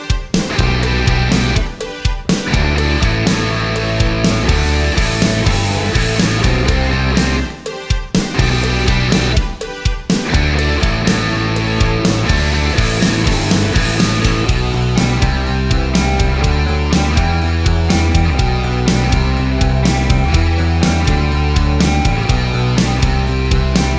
no Backing Vocals Rock 4:10 Buy £1.50